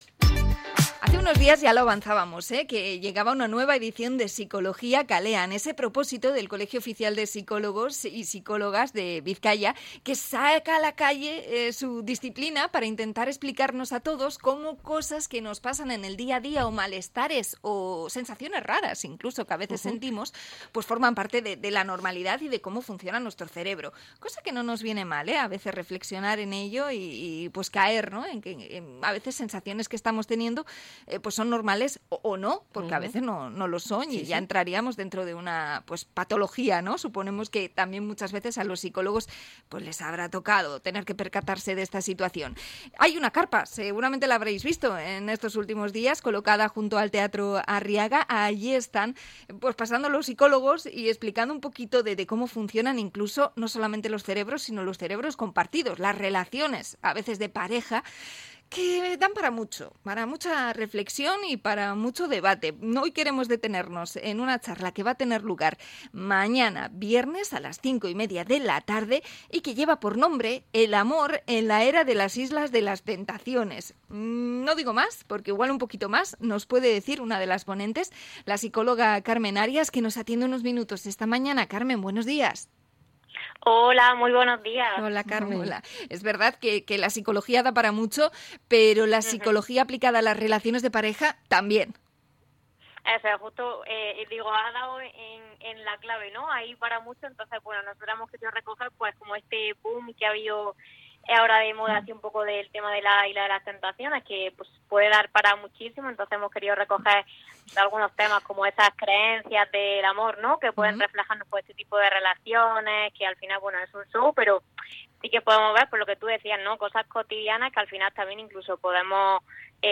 Entrevista a psicóloga del COP sobre las relaciones de pareja